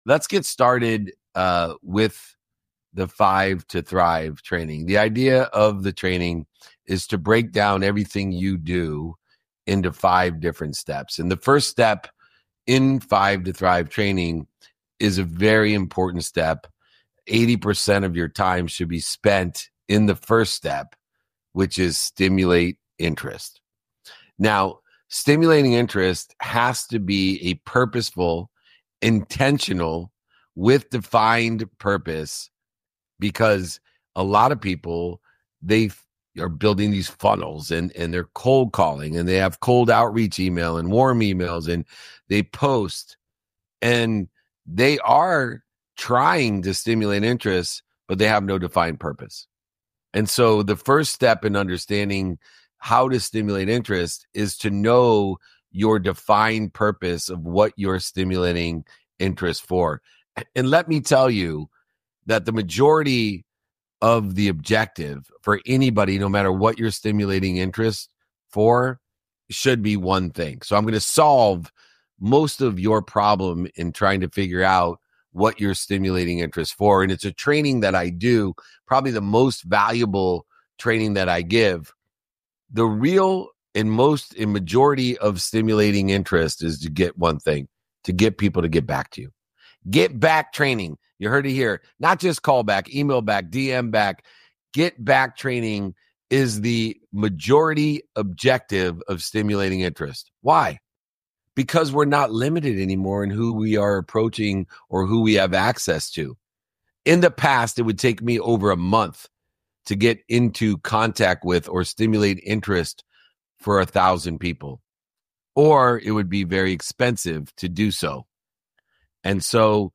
In this training session, I shared the Five to Thrive sales system—a powerful approach to creating long-lasting relationships and building a thriving community. This system focuses on stimulating interest, transitioning it into shared goals, managing and developing visions, and ultimately creating a network of advocates who both buy from you and sell for you.